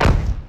MB Kick (43).wav